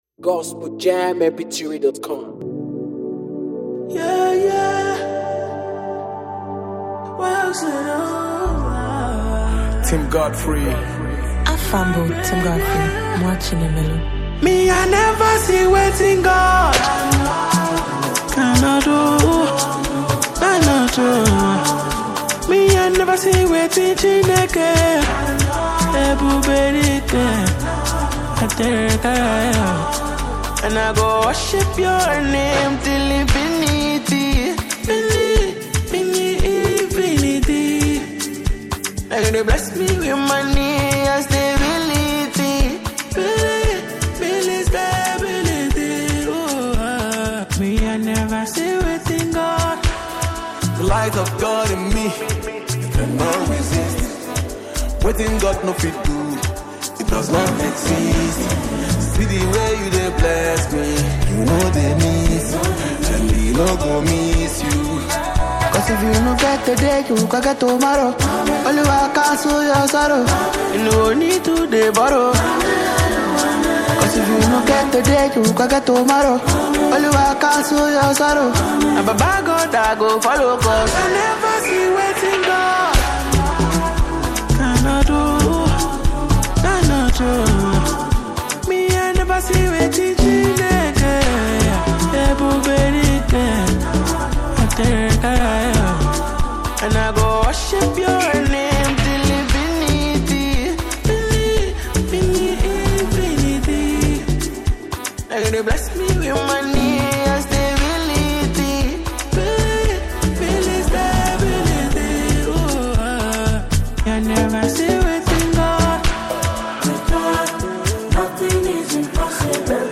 This yields a crossover sound.